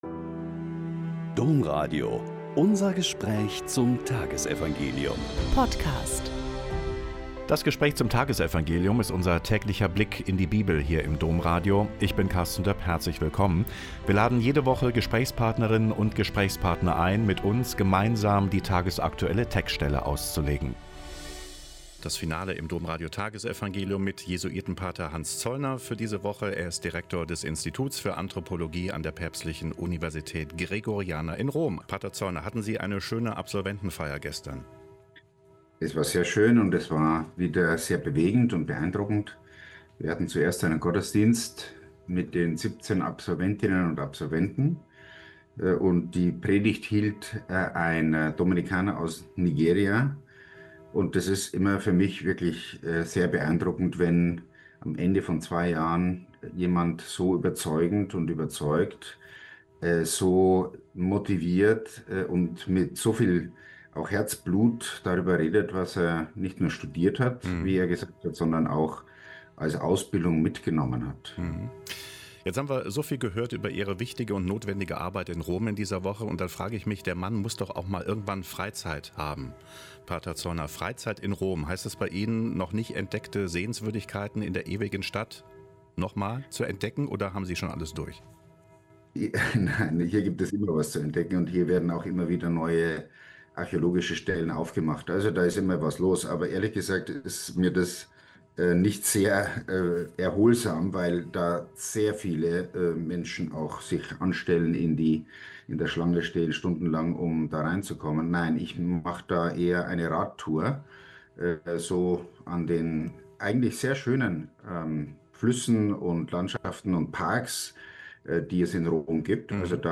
Mt 5,33-37 - Gespräch mit Pater Hans Zollner SJ